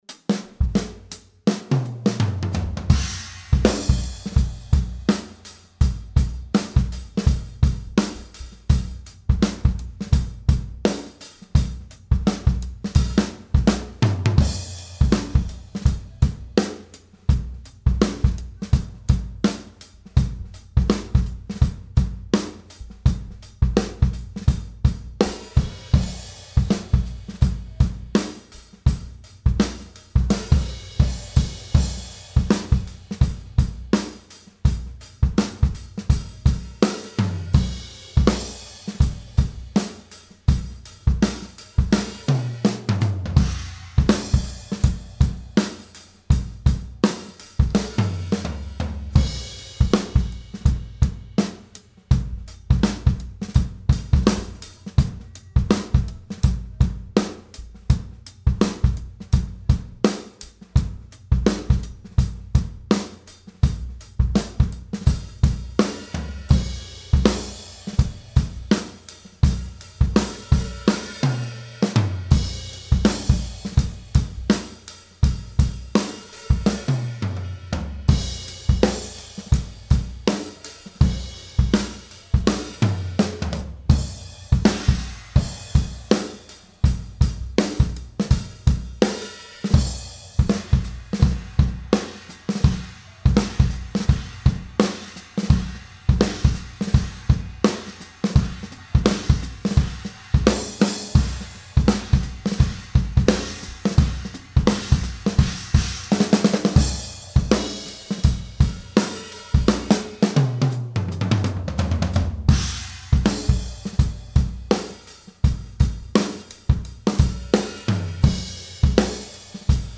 So ist dann auch der ganze Raumklang auf dieser Aufnahme aus diesem Raum, es sind keine Raumeffekte im Einsatz. Wer genau hinhört, kann irgendwo die Pilotstimme des Sängers im Hintergrund hören und damit das Stück erraten... :)
Drums für gerade entstehendes Demo
Drums: Pearl BLX 22x16, 12x10, 14x12, 16x16, Snare BLX 13 x 6.5, Felle BD PS3 vorne und hinten, Toms Evans Gplus clear über Remo Diplomat, Snare Powerstroke Coated über Amba Reso.
Becken: Paiste Signature 18" Mellow Crash, 14" Full Crash und 16" Thin China. Zildjian K Custom 1$Dark Hihat und 20" Custom Ride.
Overheads AKG C414 B-ULS, Raummikros Sennh. MD441U3.
Das Demo ist noch in Arbeit - die Schnipsel, die ich hier teile, sind im Prinzip Roughmixe.